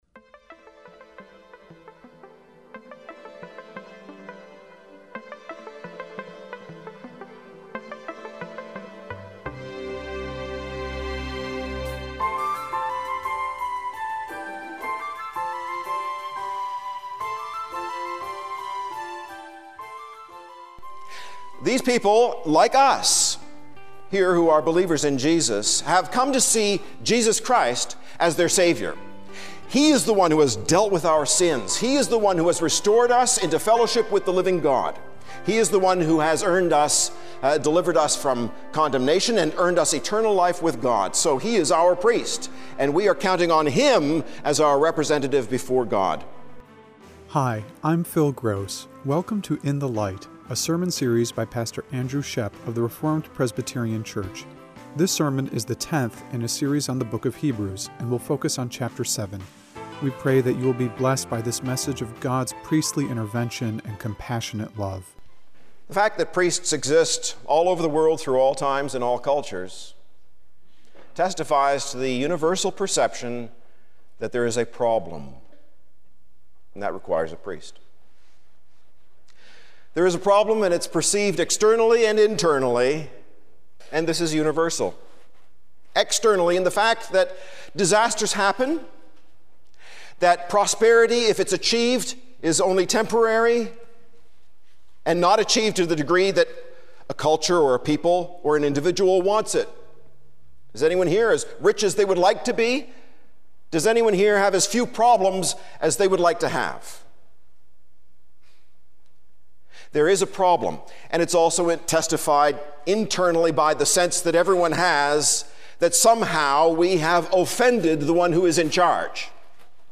Sermon
Radio Broadcast